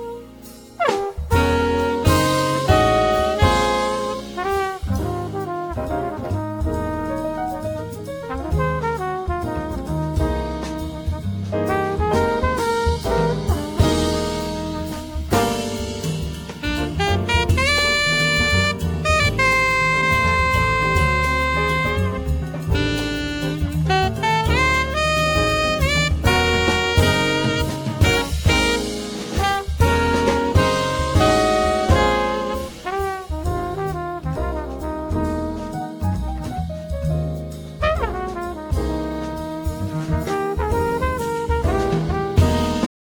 trumpet
piano
bass
drums